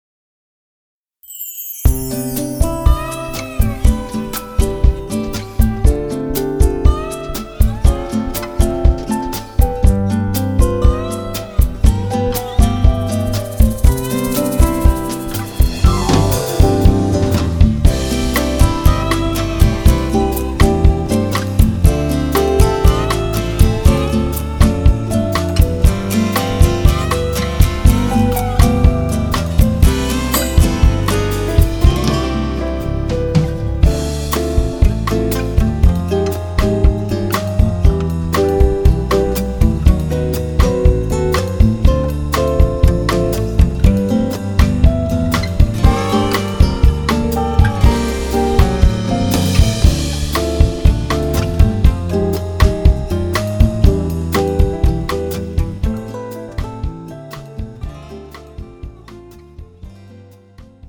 장르 가요 구분 Premium MR